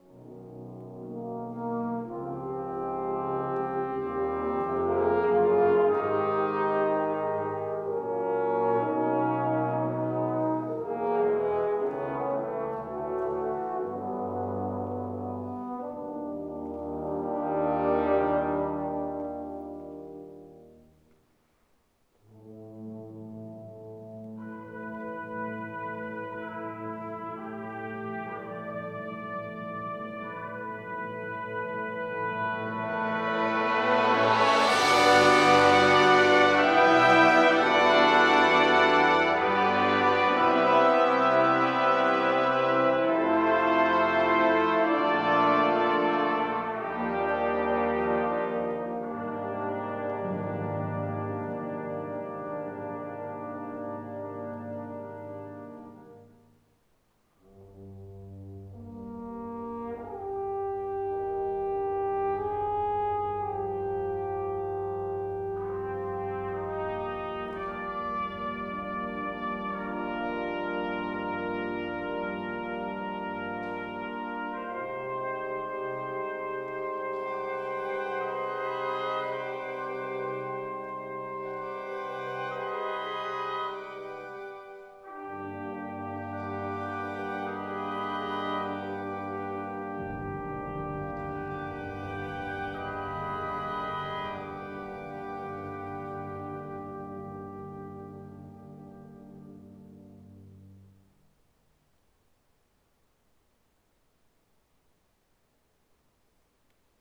Sample #2: Wind Symphony (01:45) (9.4MB/file).
B-format files for 3 microphones.